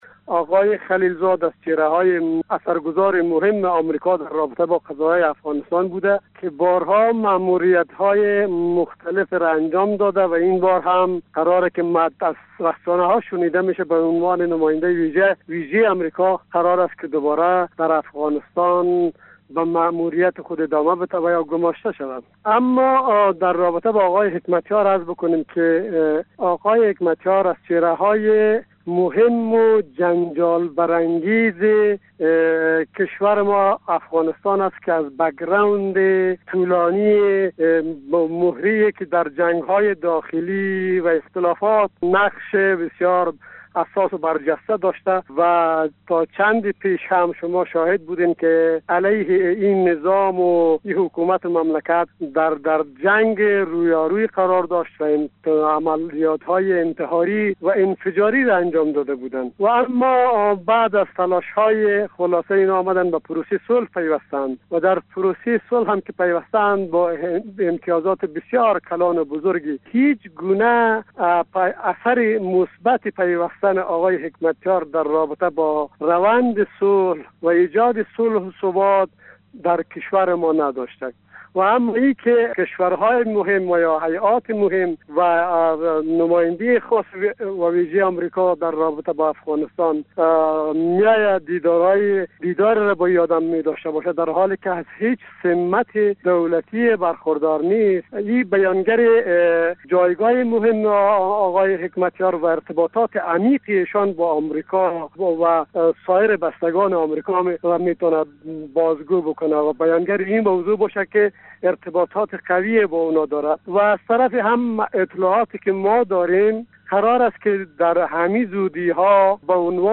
کارشناس سیاسی افغان
گفت و گو